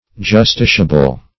Search Result for " justiciable" : The Collaborative International Dictionary of English v.0.48: Justiciable \Jus*ti"ci*a*ble\, a. [Cf. LL. justitiabilis, F. justiciable.] Proper to be examined in a court of justice.